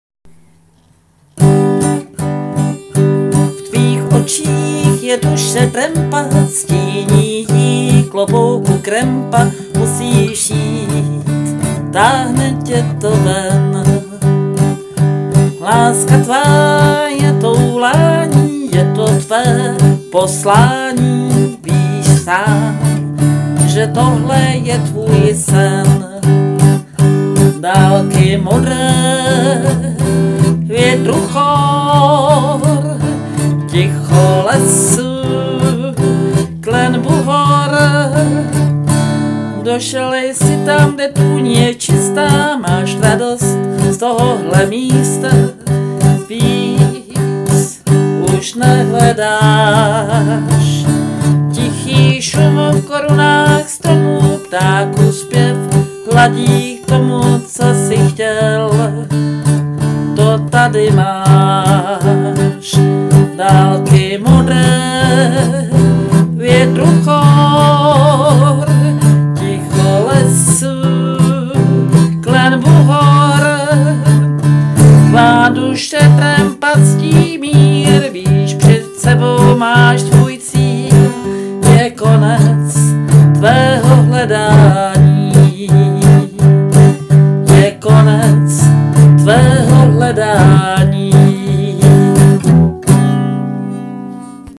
Festival trampských písní